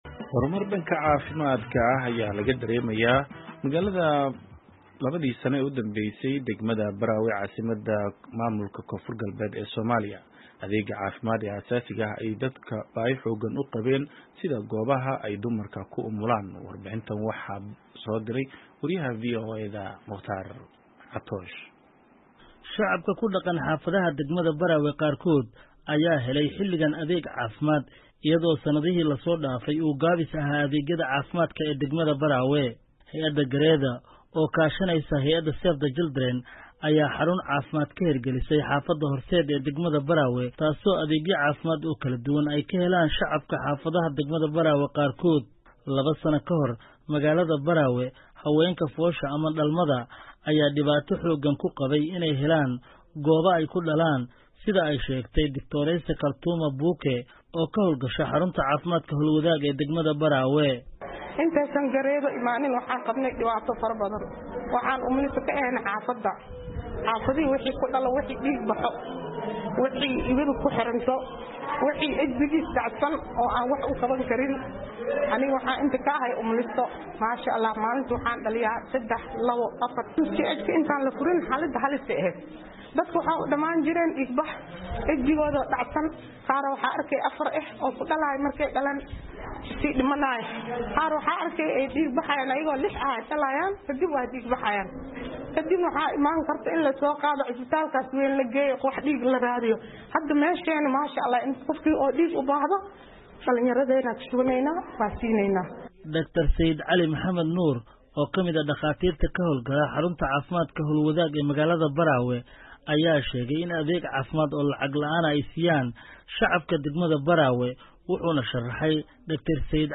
Warbixin ku saabsan adeegyada caafimaad ee degmada Baraawe